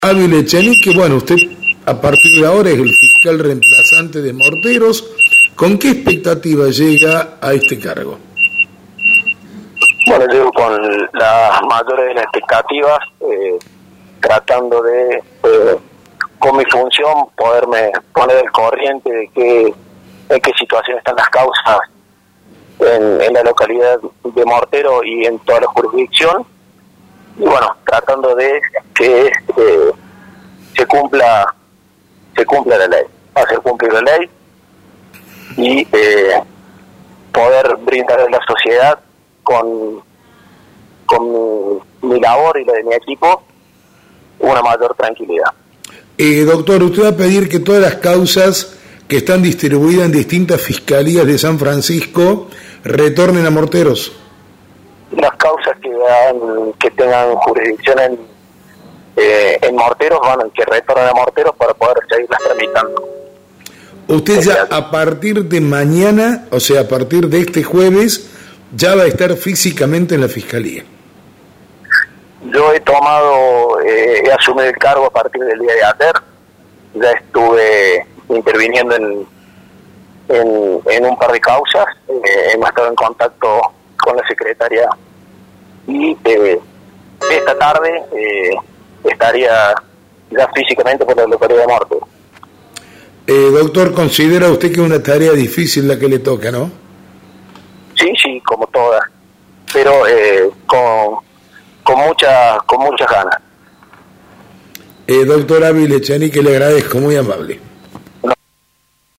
En un contacto mantenido con LA MAÑANA DE LA RADIO,  Ávila Echenique adelantó que solicitará que las causas que se originaron en la jurisdicción que el corresponde y que se encuentran en diferentes fiscalías de San Francisco, retornen a Morteros.